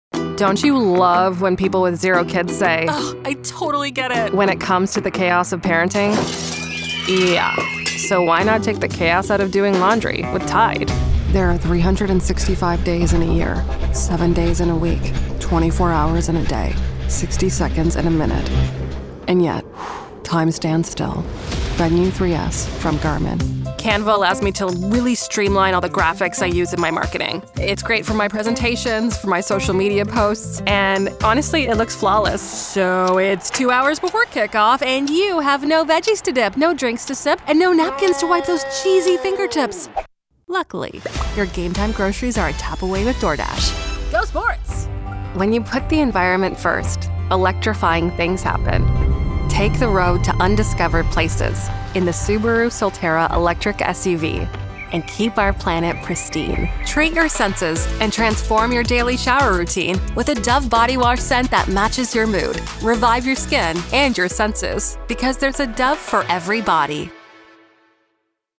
I can deliver a read that is natural, conversational, and warm; bubbly and friendly; informative and confident.
Sennheiser MKH 416 Microphone